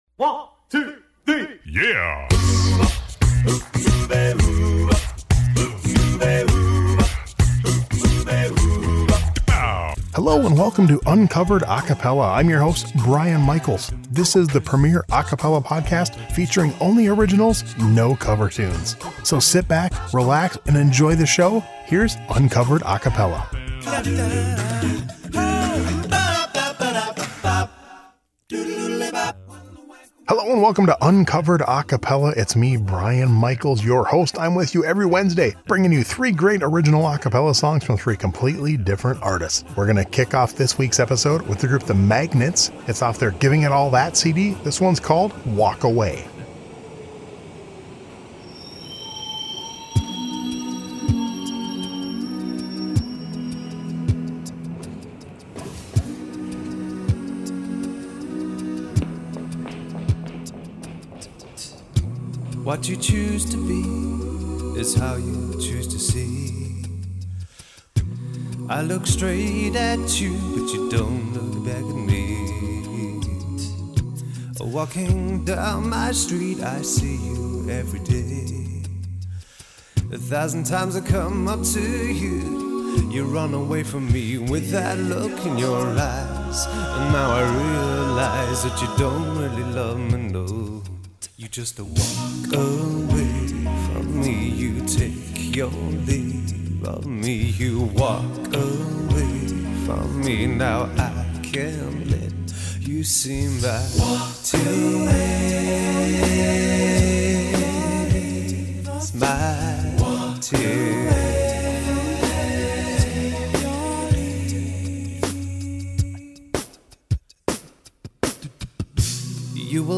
On this week’s episode we hear original songs